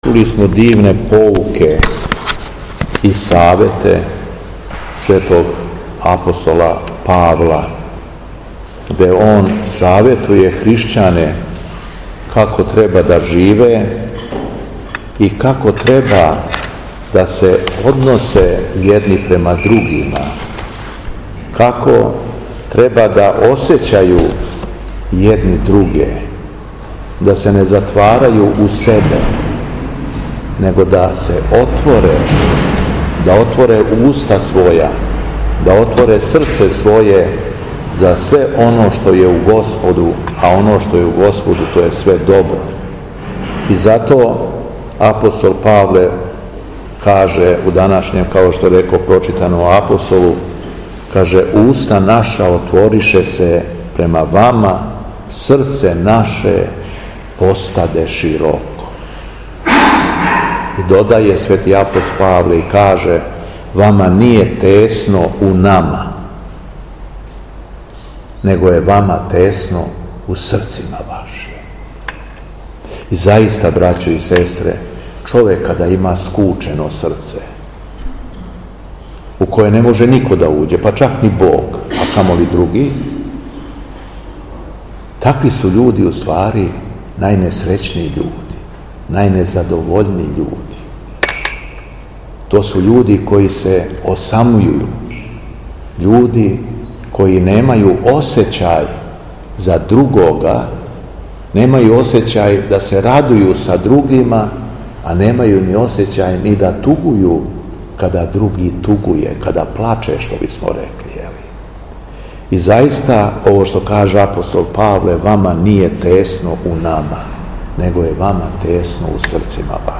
Беседа Његовог Високопреосвештенства Митрополита шумадијског г. Јована
У среду 27. августа, када Црква молитвно прославља Светог пророка Михеја Другог (Претпразништво Успенија) Његово Високопреосвештенство Митрополит шумадијски Господин Јован служио је свету архијерејску литургију у храму Светога великомученика кнеза Лазара у Белошевцу уз саслужење братсва храма.